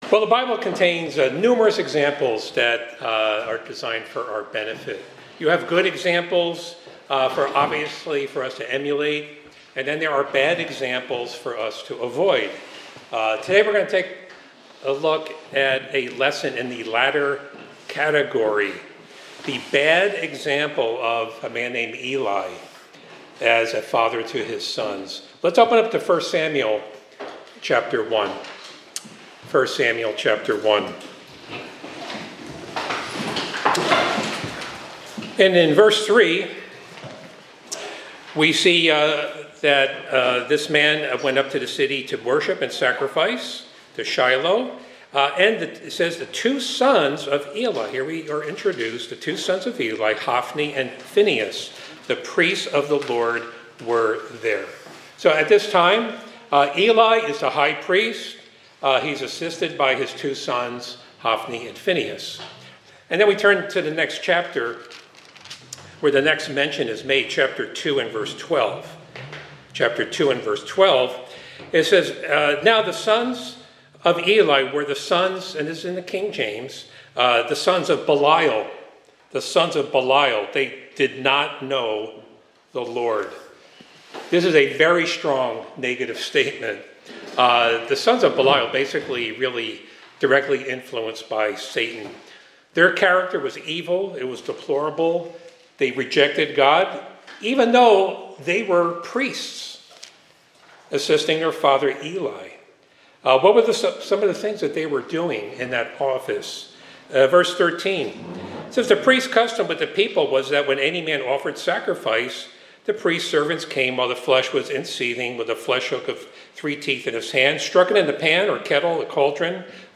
This Sermonette focuses on the biblical lessons found in the story of Eli, the high priest, and his failures as a father to his sons, Hophni and Phinehas. It emphasizes the importance of parental responsibility and the consequences of neglecting the proper discipline of our children.
Given in Hartford, CT